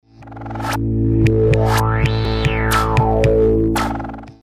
типа фильтра МУГа
примерный аудиообразец этого фильтра не на басе